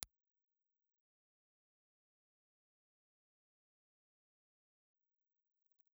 Impulse Response File:
IR file of a Lustraphone VR64 ribbon microphone.
Response substantially flat 50 to 13 000 cfs.